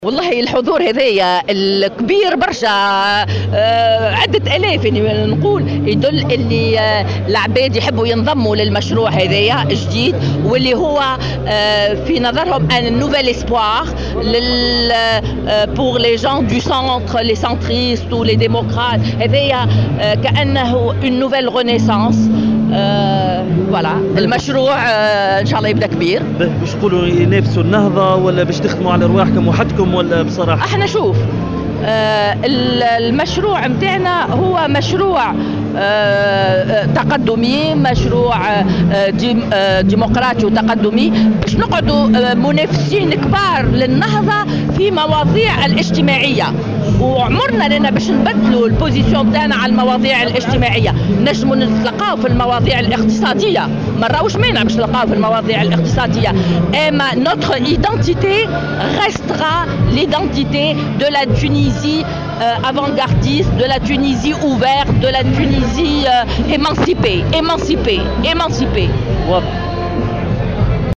وقالت في تصريح لمراسل "الجوهرة اف أم" على هامش اجتماع جهوي للمشروع السياسي الجديد الذي ينسب لرئيس الحكومة يوسف الشاهد، إن هذا المشروع تقدمي وديمقراطي، مردفة بالقول: سننافس النهضة في المواضيع الاجتماعية في المقابل يمكن الالتقاء معها في المواضيع الاقتصادية، وفق تعبيرها.